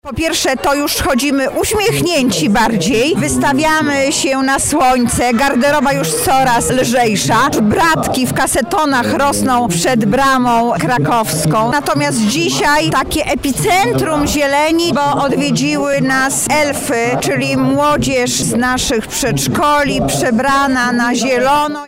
Lubelskie przedszkolaki oficjalnie pożegnały zimę. Korowód najmłodszych mieszkańców Lublina powita nową porę roku w rytmie rapu i miejskiego hejnału.
Zamiast tradycyjnego topienia marzanny, dzieci wzięły udział w interaktywnym finale, podczas którego wspólnie zarapowały o odejściu mrozów, przy akompaniamencie wiosennej melodii.
dzwiek-dzieci.mp3